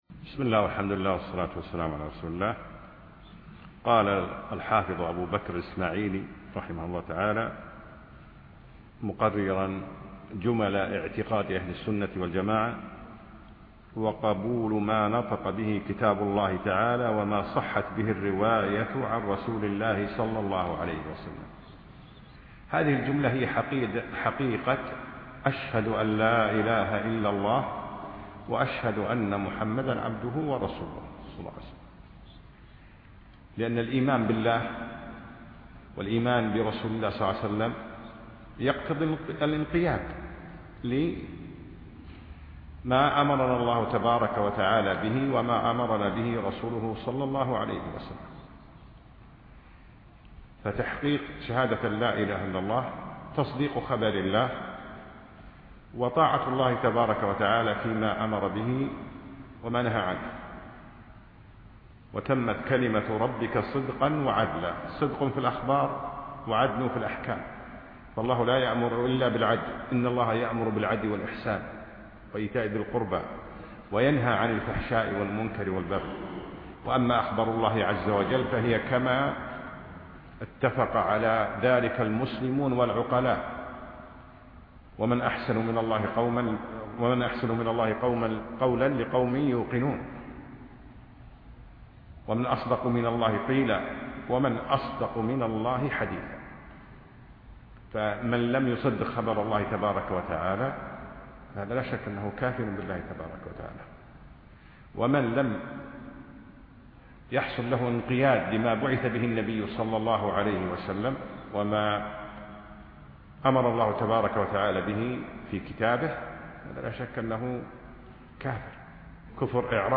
أقيمت الدورة في دولة الإمارات
الدرس الثاني